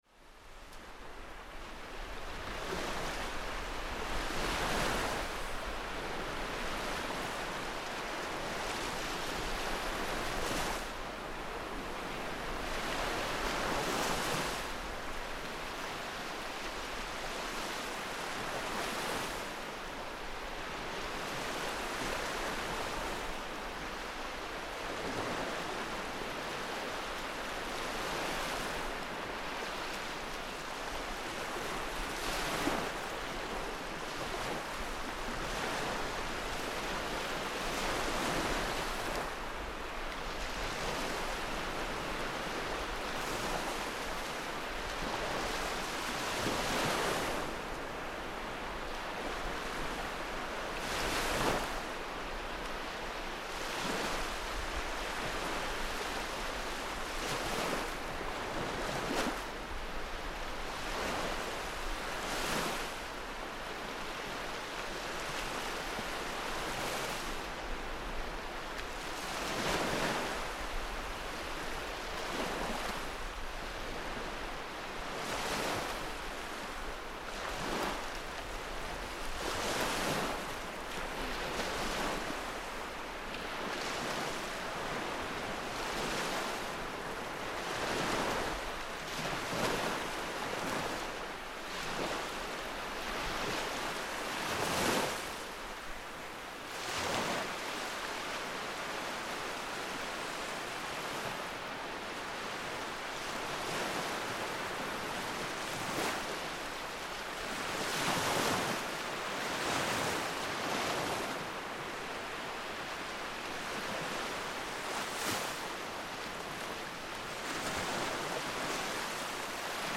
Waves breaking on a sandy beach
Waves break on a sandy beach on a late August afternoon, Rosalina Mare, Italy.